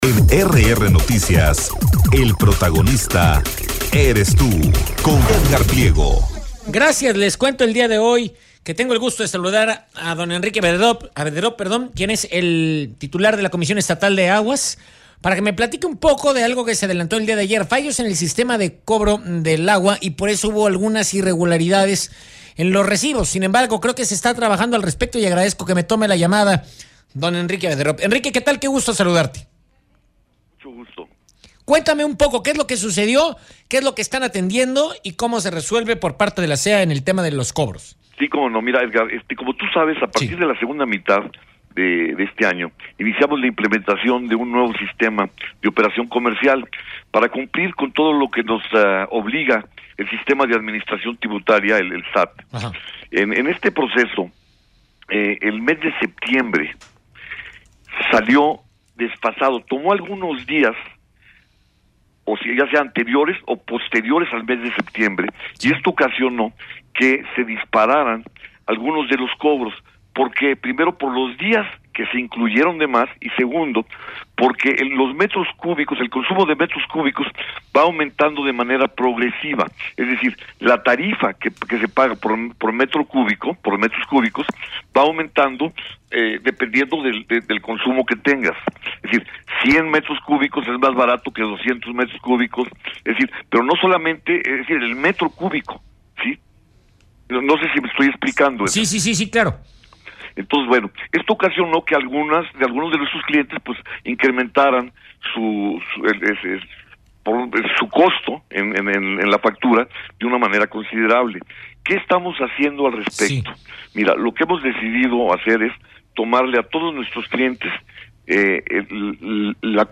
ENTREVISTA-ENRIQUE-ABEDROP-VOCAL-EJECUTIVO-DE-LA-CEA.mp3